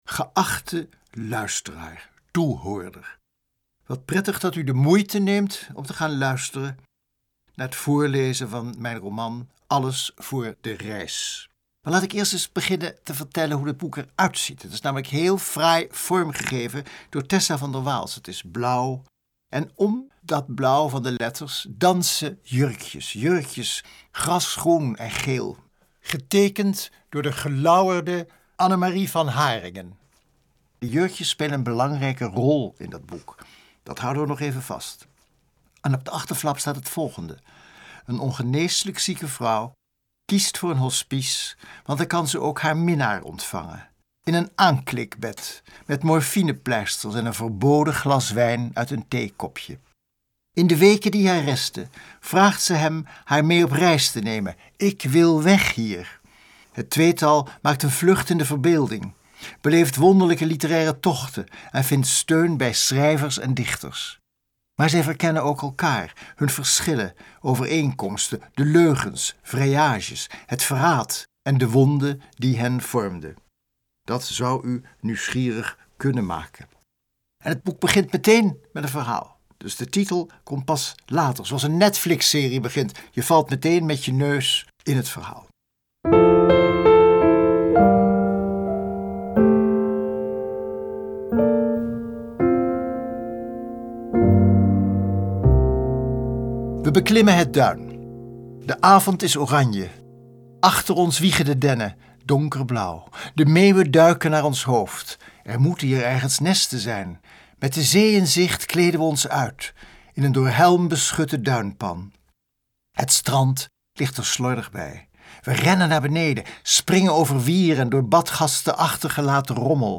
Alles voor de reis Auteur: Adriaan van Dis Luister een fragment Direct bestellen Een aangrijpende roman over een onvergetelijke vrouw Een ongeneeslijk zieke vrouw kiest voor een hospice want daar kan ze ook haar minnaar ontvangen.